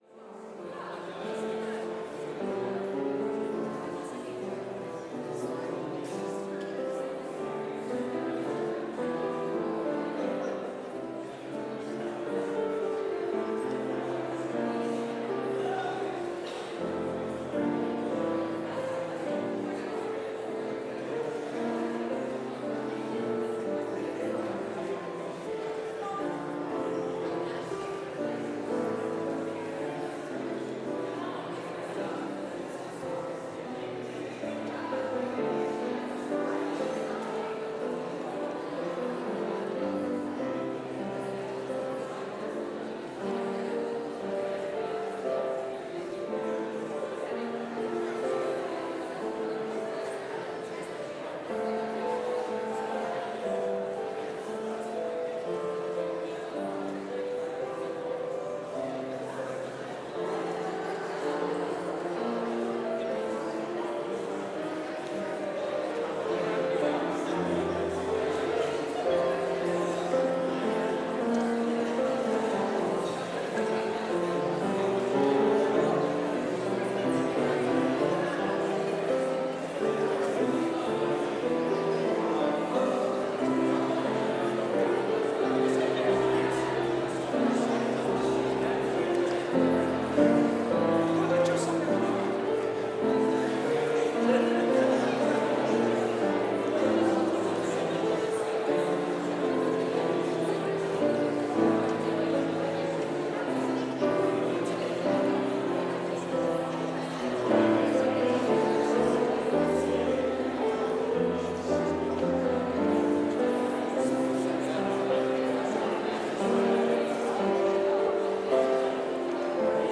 Complete service audio for Chapel - Thursday, September 12, 2024